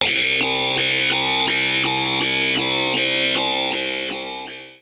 Metal1.wav